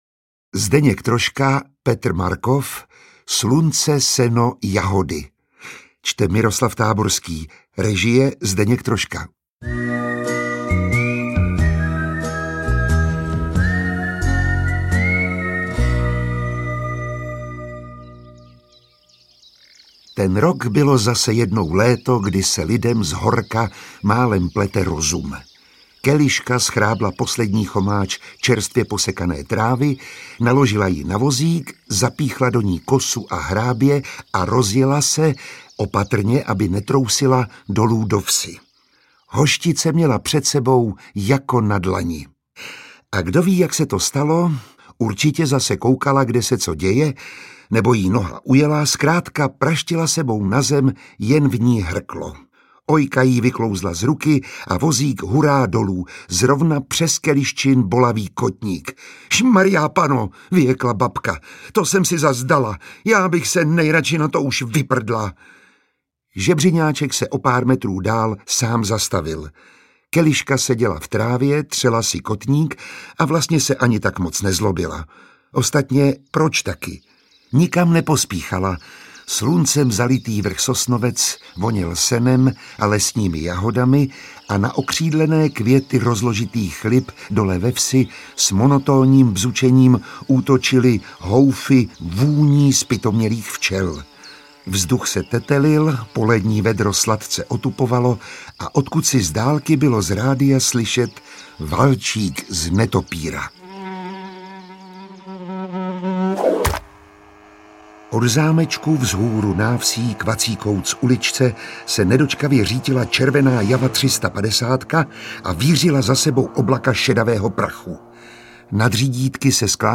Interpret:  Miroslav Táborský
Literární zpracování legendárních filmových komedií v audioknižní podobě. Čte Miroslav Táborský, režie Zdeněk Troška. V jihočeské vesničce Hoštice se objeví student vysoké školy, aby v místním JZD prověřil svůj experiment na téma „dojivost krav v závislosti na kultuře prostředí“.